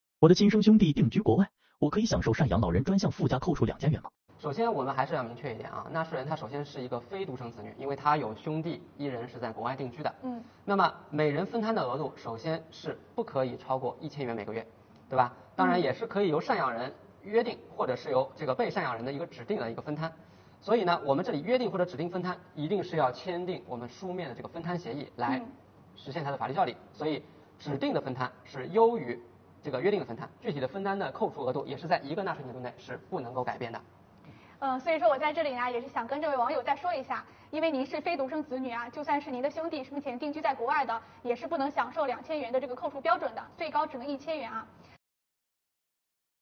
【涨知识】非独生子女能否享受每月2000元赡养老人税前扣除？来听听主播怎么回答~